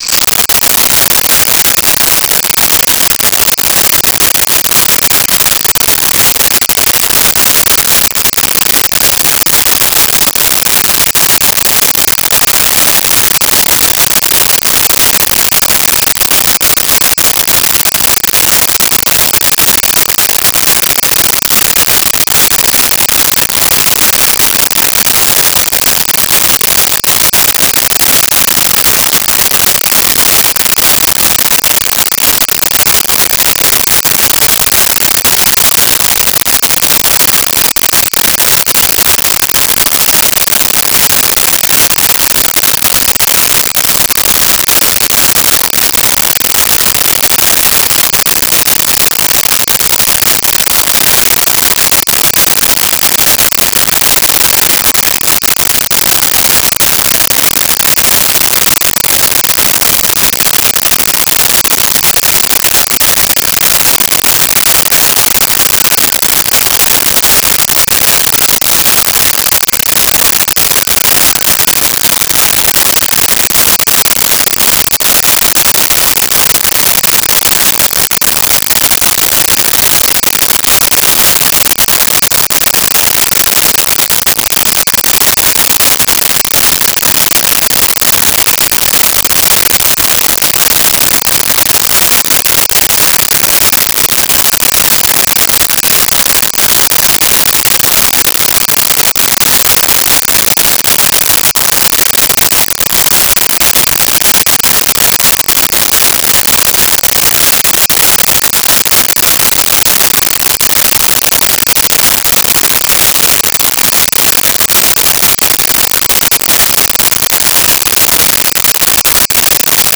Jungle Insects With Frogs
Jungle Insects With Frogs.wav